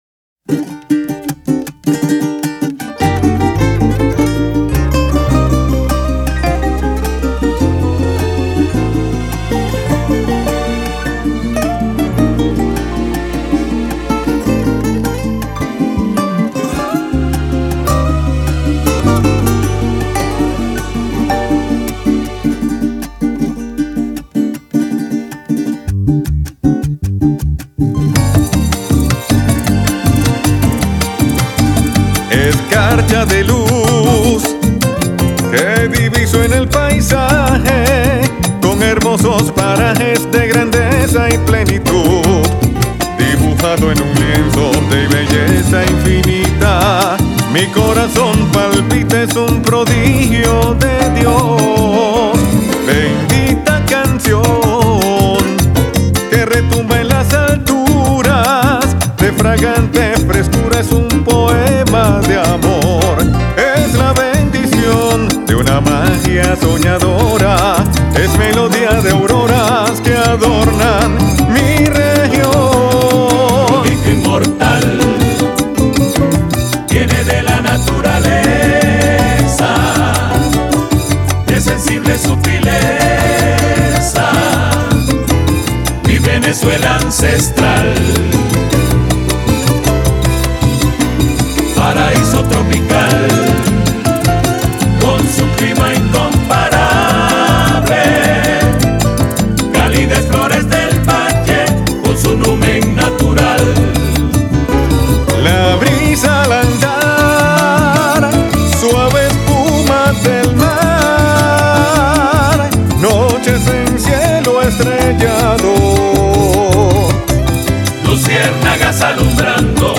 agrupación